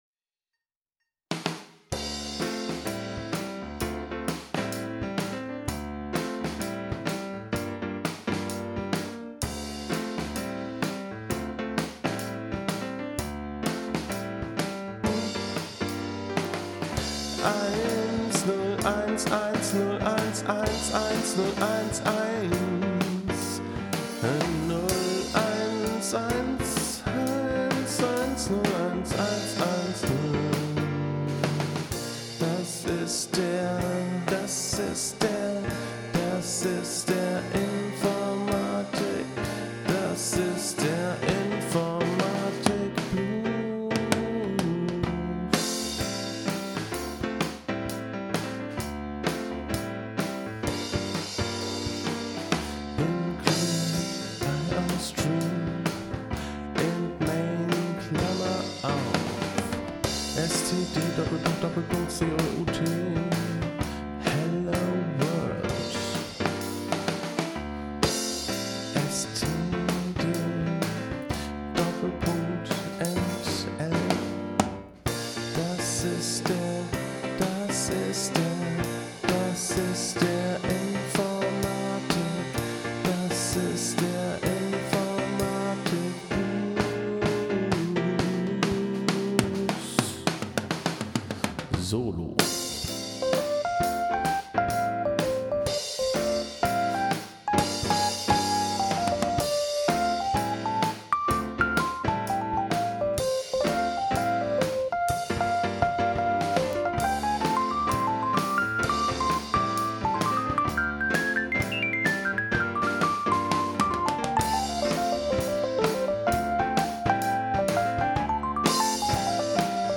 Es ging mir vor allem darum, meiner Kreativität freien Lauf zu lassen und gleichzeitig mein kleines Heimstudio auszuprobieren.
• Alles wurde an einem Tag geschrieben, gespielt, aufgenommen und produziert.